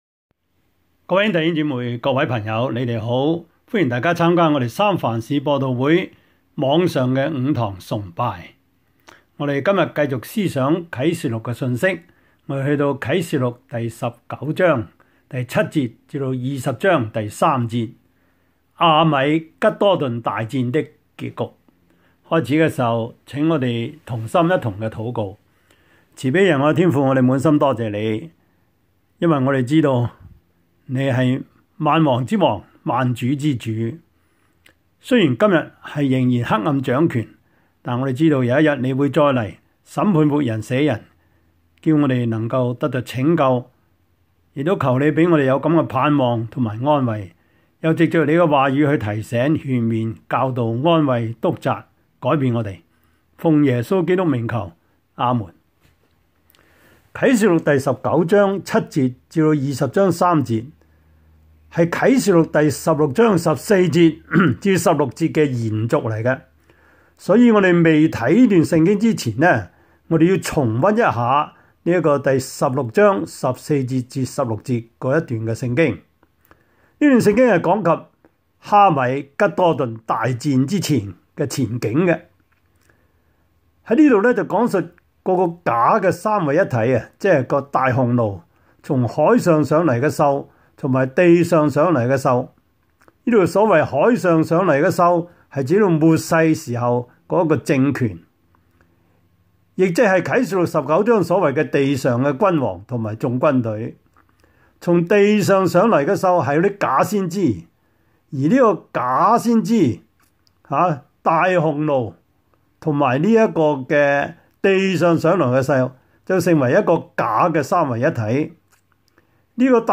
Service Type: 主日崇拜
Topics: 主日證道 « 導航定位 – 請問你的位置在那裡?